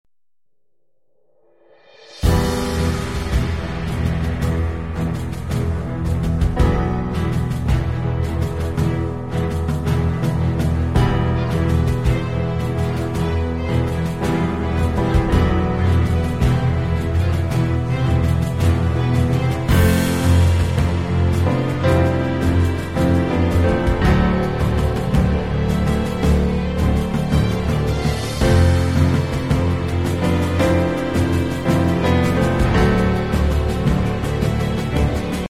The fox in the jungle sound effects free download